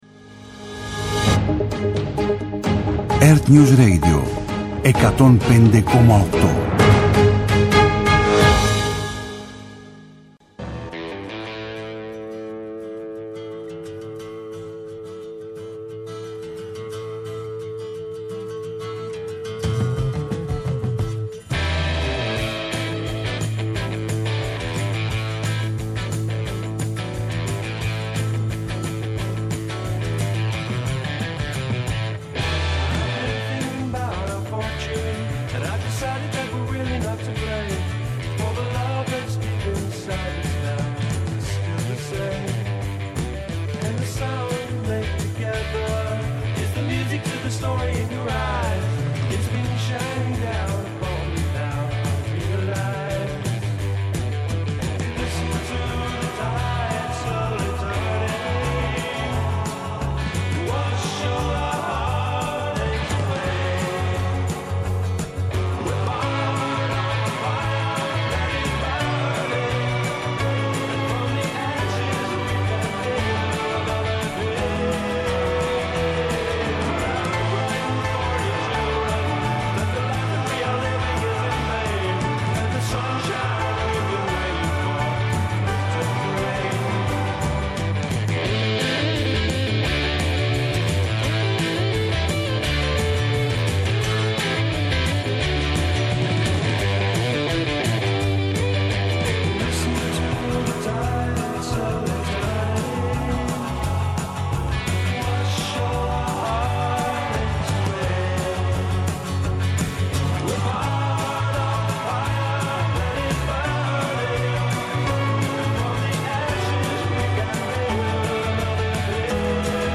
progressive
ΜΟΥΣΙΚΗ